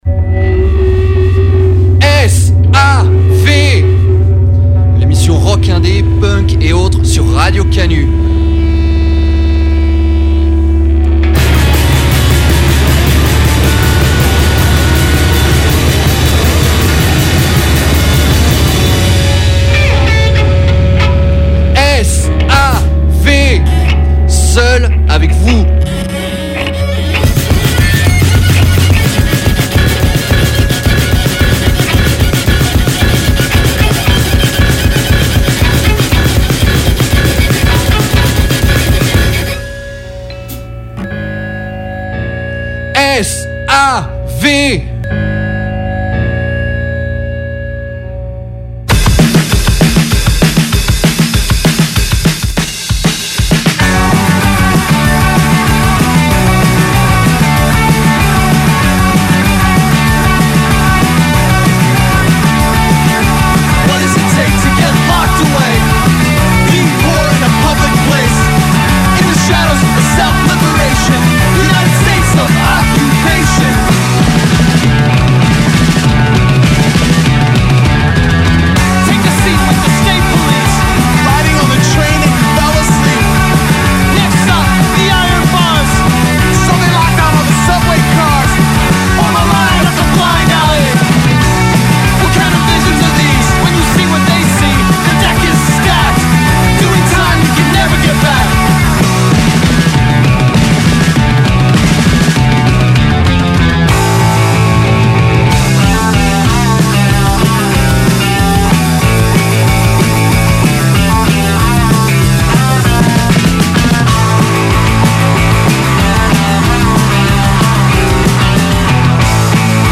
Une sélection de nouveautés punk/indie/hardcore !